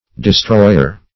Destroyer \De*stroy"er\, n. [Cf. OF. destruior.]